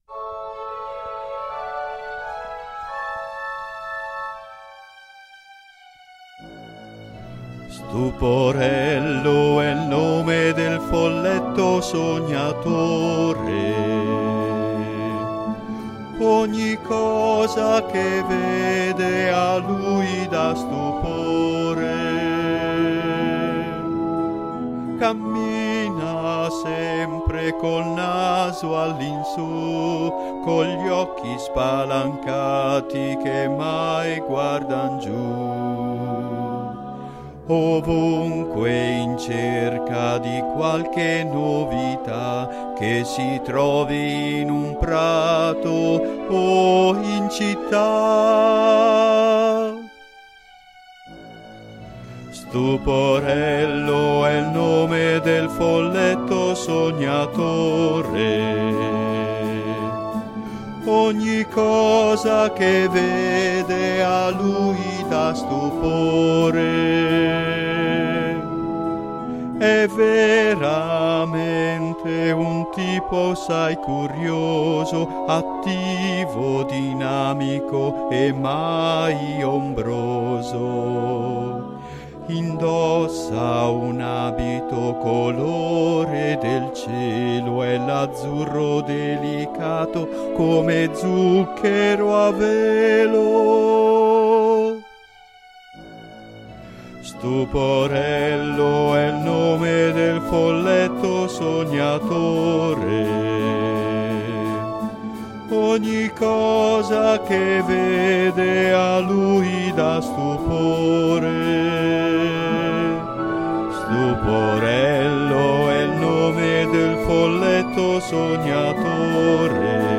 Canzoni dedicate ai sei Folletti scritte musicate e cantate